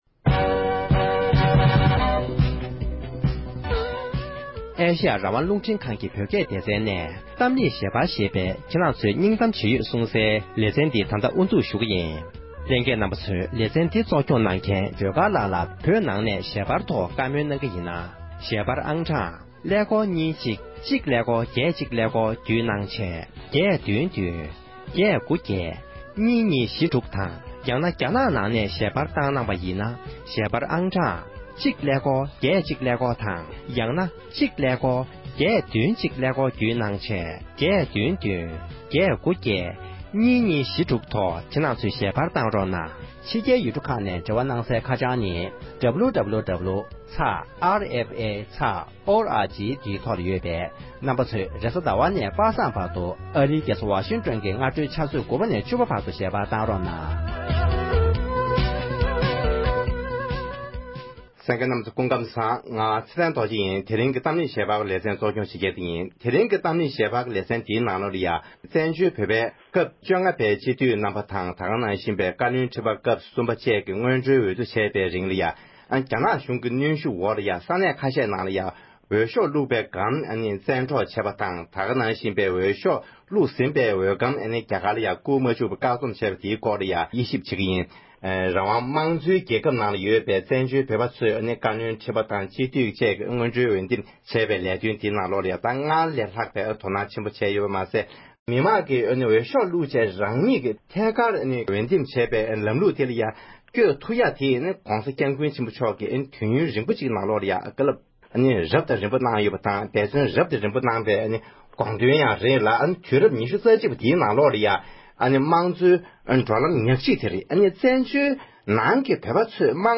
བལ་ཡུལ་དང་འབྲུག་ཡུལ་དུ་བཀའ་སྤྱིའི་སྔོན་འགྲོ་འོས་བསྡུའི་ལས་དོན་ལ་བཀག་སྡོམ་བྱས་པའི་ཐད་བགྲོ་གླེང༌།